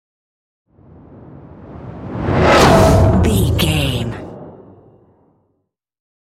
Dramatic whoosh to hit trailer
Sound Effects
dark
futuristic
intense
woosh to hit